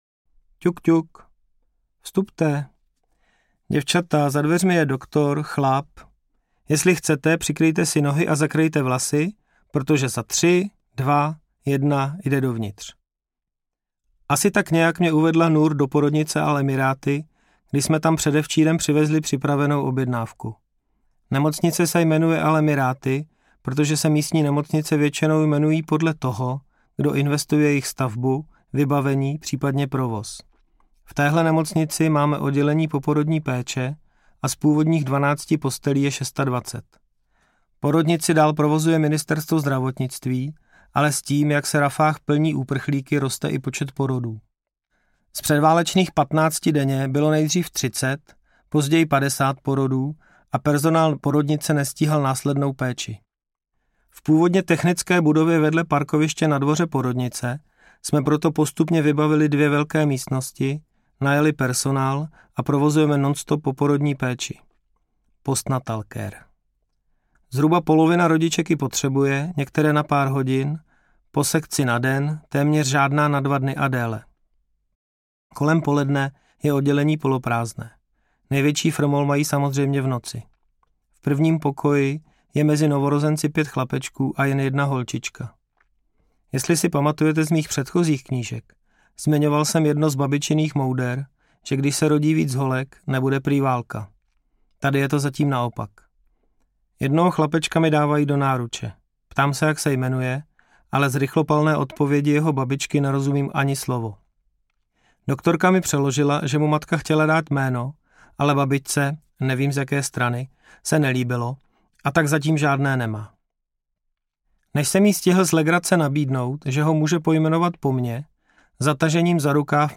Audio knihaNebe plné draků
Ukázka z knihy
nebe-plne-draku-audiokniha